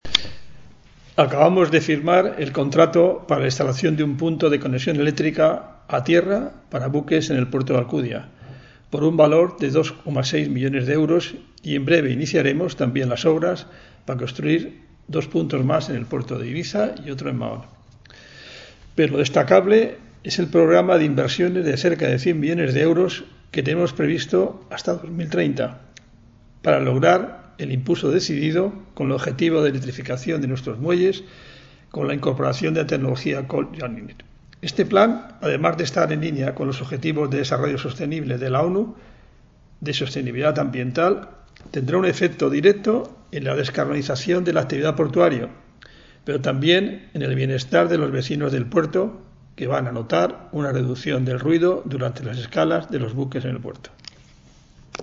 El presidente de la APB, Javier Sanz, destaca
Declaracions Javier Sanz.MP3